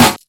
• Sparkle Snare Single Hit B Key 111.wav
Royality free acoustic snare sound tuned to the B note. Loudest frequency: 2701Hz
sparkle-snare-single-hit-b-key-111-60t.wav